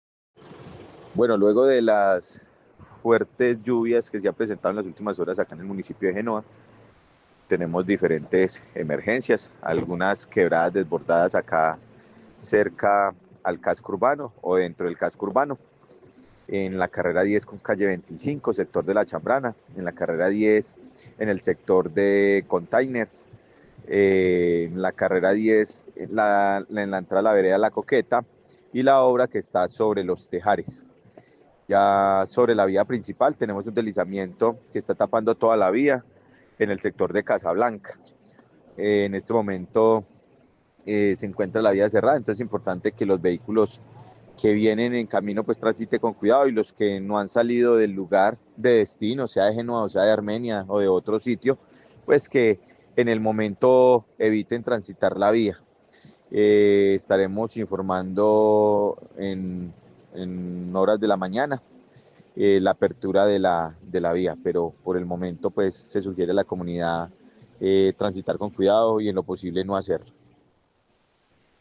Alcalde de Genova, Diego Fernando Sicua Galvis